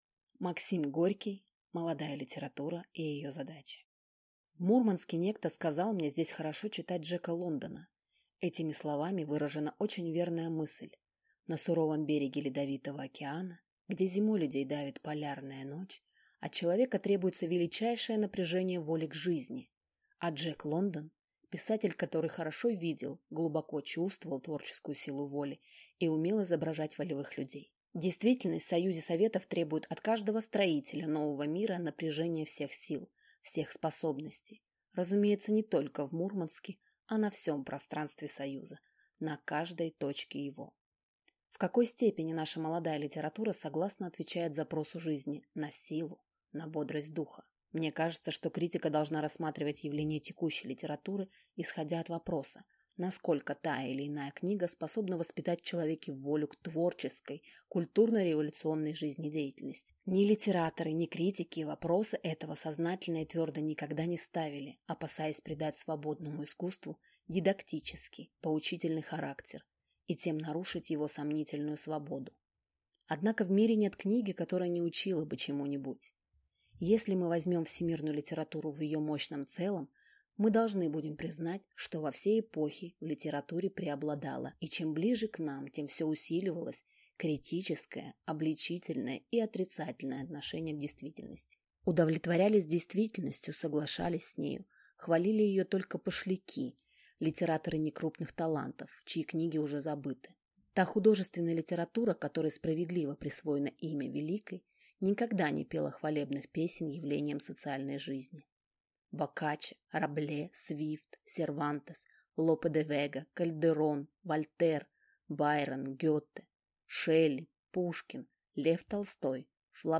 Аудиокнига Молодая литература и её задачи | Библиотека аудиокниг